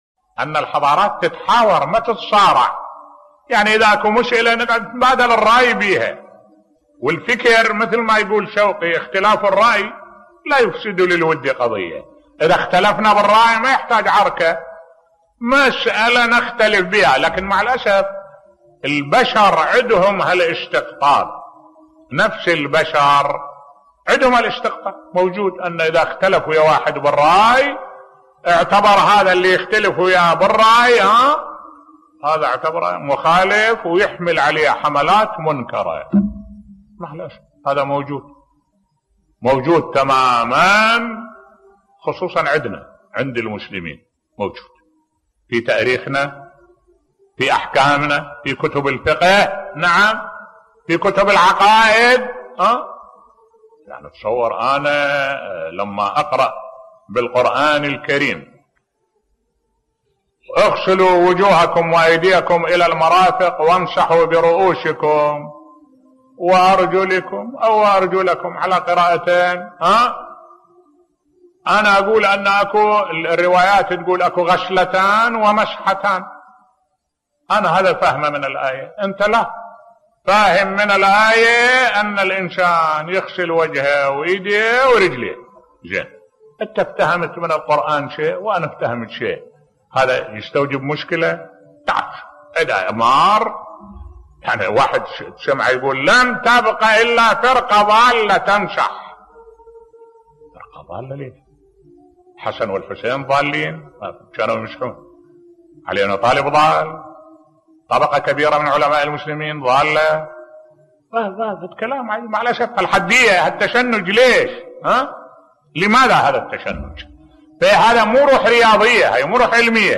ملف صوتی إختلاف الرأي لا يفسد للود قضية بصوت الشيخ الدكتور أحمد الوائلي